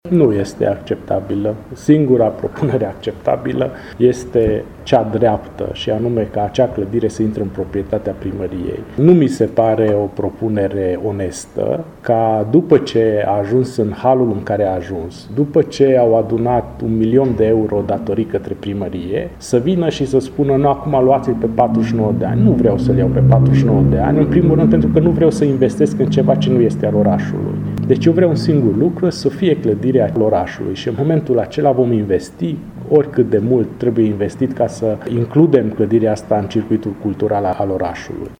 a declarat pentru Radio Tîrgu Mureș, primarul Antal Arpad.